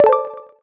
skill_select_01.wav